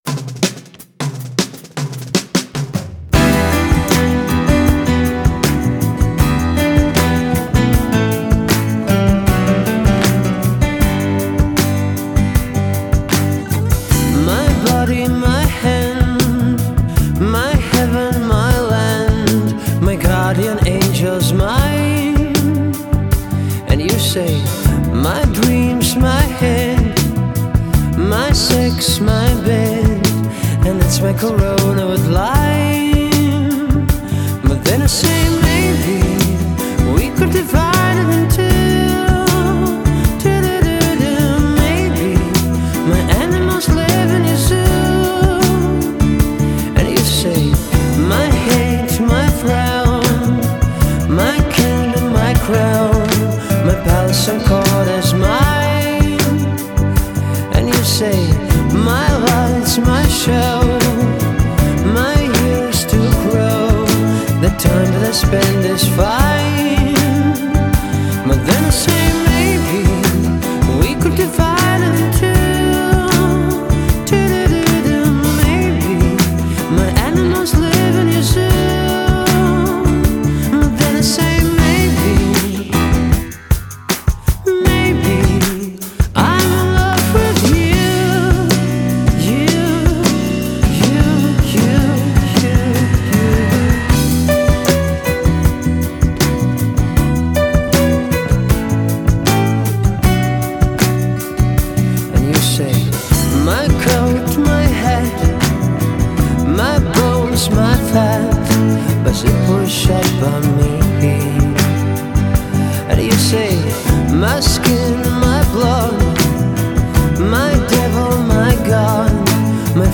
Медляки , медленные песни